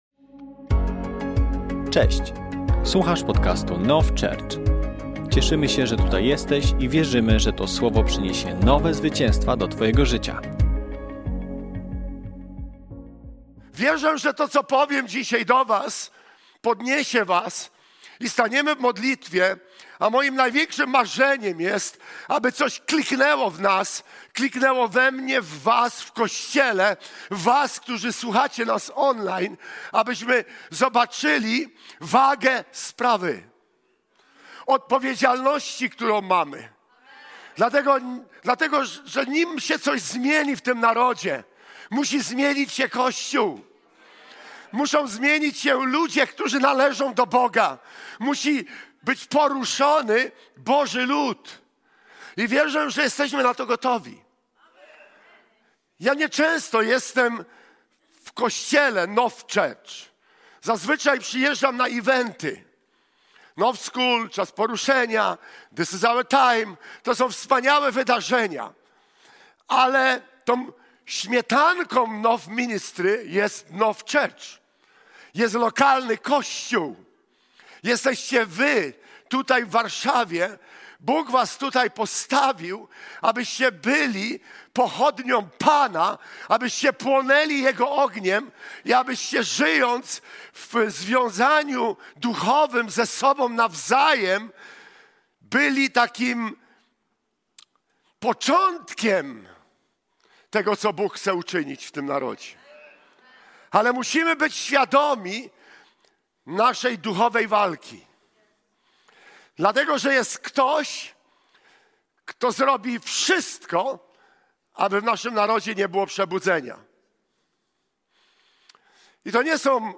Kazanie zostało nagrane podczas niedzielnego nabożeństwa NOF Church 23.03.2025 r.